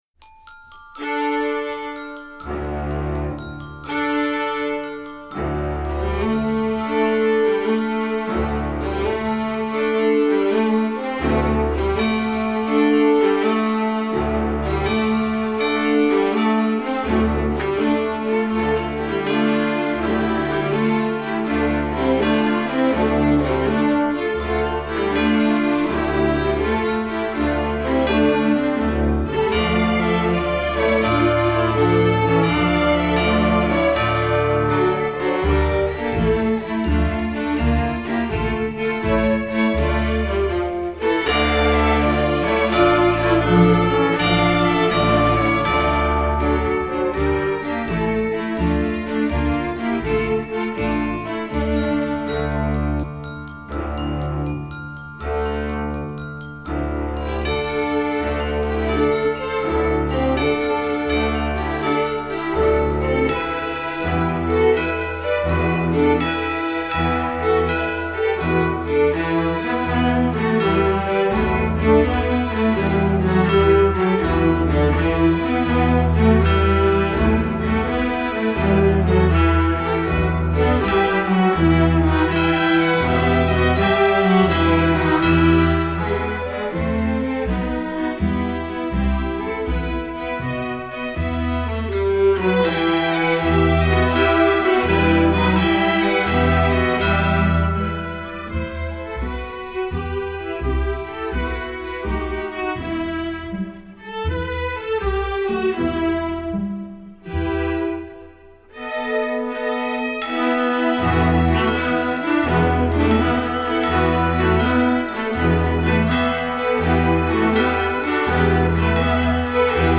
Composer: Traditional French
Voicing: String Orchestra